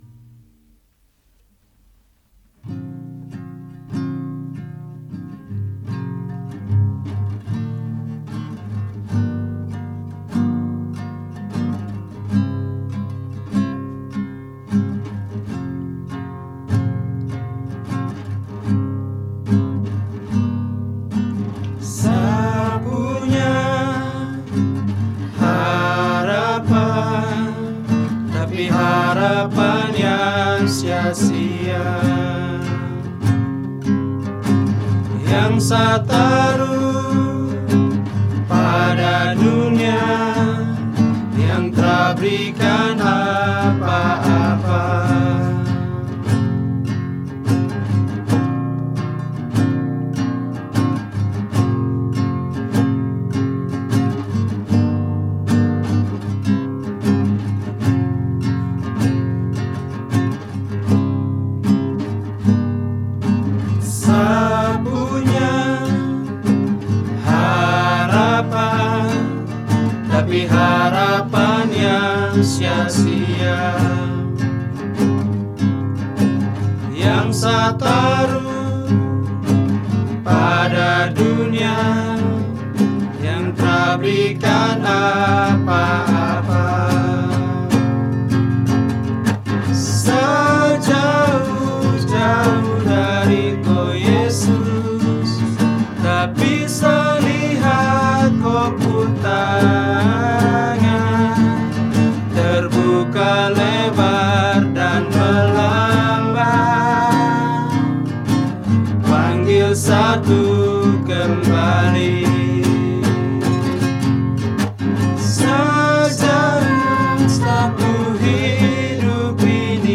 Lagu Rohani | Melayu Papua Official